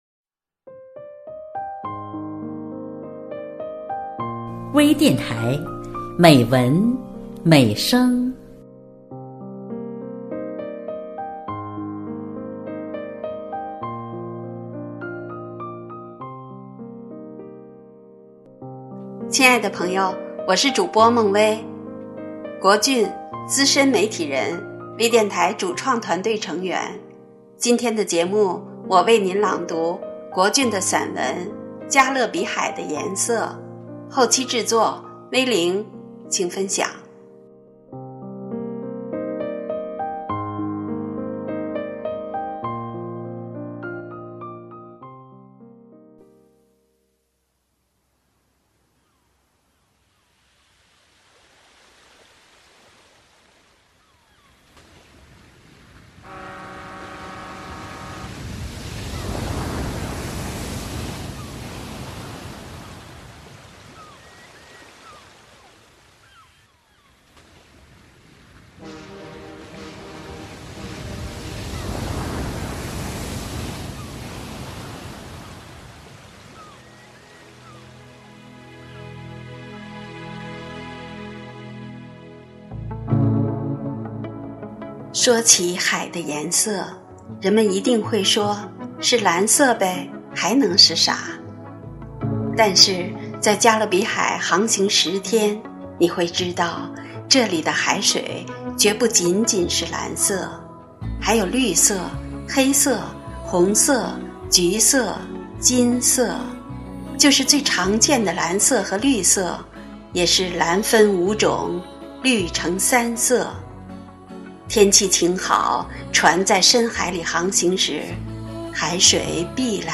多彩美文  专业诵读
朗 诵 者